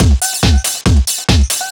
DS 140-BPM B6.wav